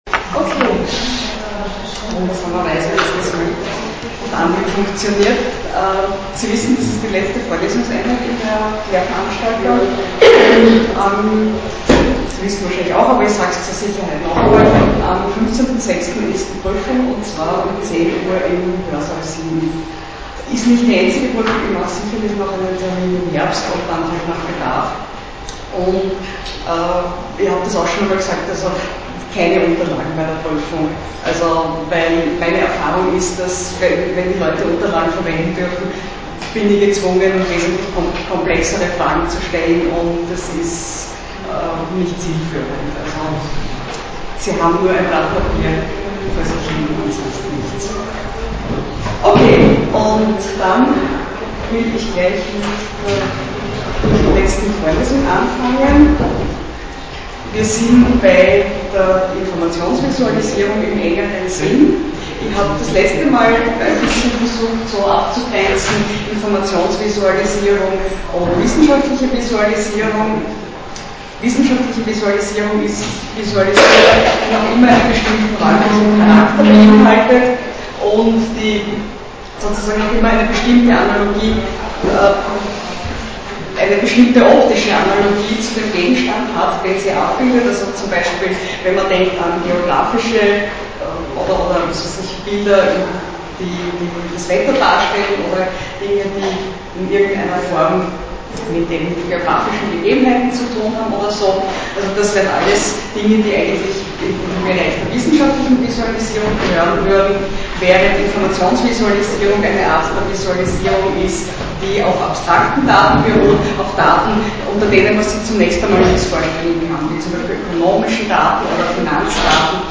Jun (10h-14h) 2005-03-16: Geschichte und Zweck von Informationsvisualisierung Audio Mitschnitt (MP3, 20 MB, 16KHz, mono, 32 kbps) Anmerkung: Werde aus Zeitgr�nden meine Mitschrift nicht online stellen.